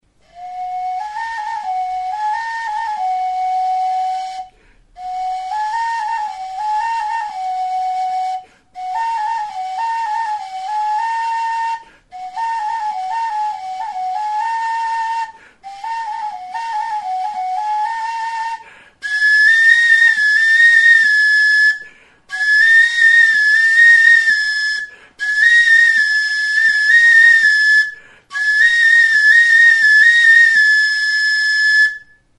Aerófonos -> Flautas -> Recta (de una mano) + flautillas
Grabado con este instrumento.
Kanaberazko tutua da. Mutur batean moko flauta motako ahokoa du, ixteko eta aire kanala egiteko takoa kortxozkoa delarik.